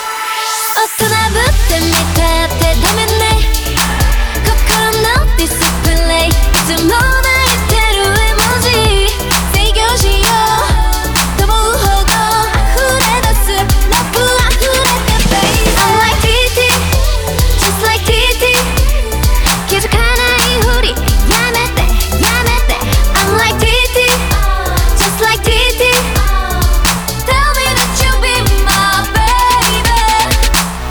・リニアPCMステレオレコーダー：OLYMPUS LS-20M
録音モードは「PCM 96khz/24bit」のハイレゾで、ファイル形式は.WAVでアップしました。
透明感のあるボーカルと重低音が特徴の音数が少ない曲なので、比較視聴に適した曲だと思います。
最初に基準としてイヤフォンを通さずアナログケーブルでイヤフォン端子と録音端子をダイレクトに接続して録音しました。